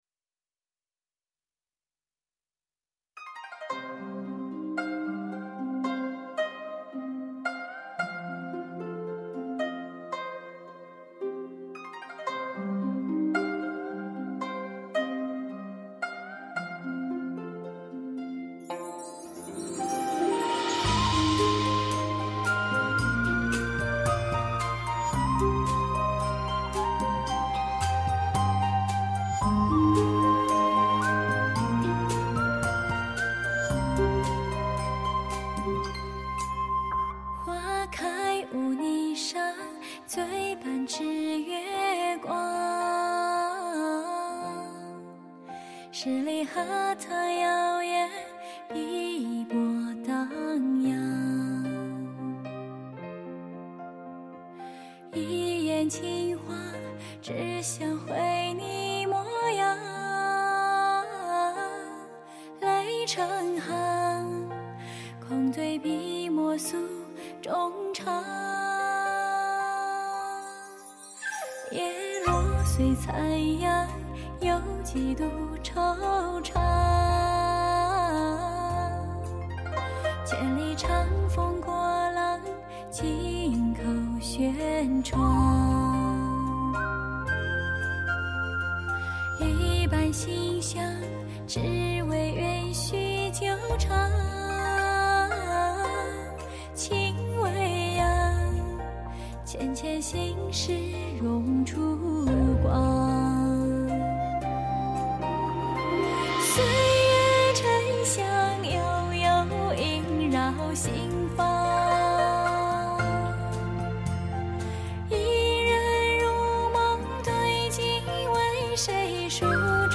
这是一首凄美又充满诗意的国风歌曲，歌词柔美而感伤，音乐流淌的时光，情绪总是变得柔软而潮湿。